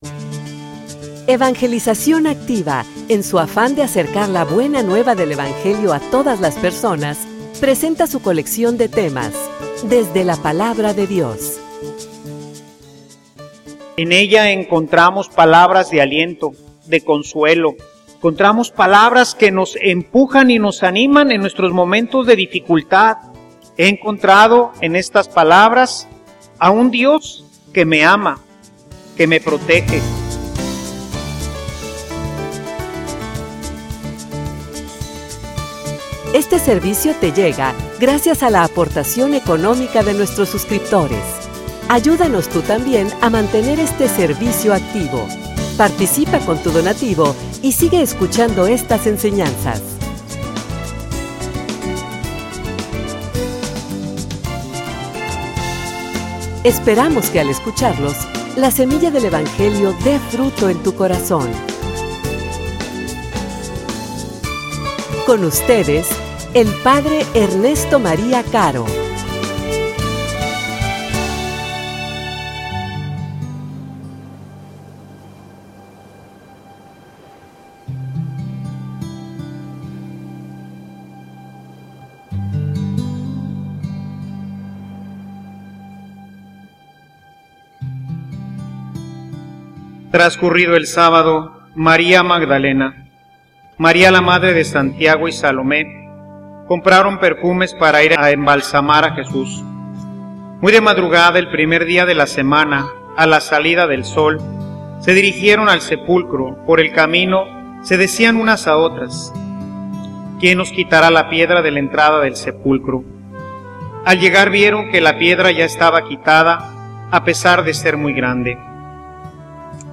homilia_Vayamos_de_vuelta_a_Galilea.mp3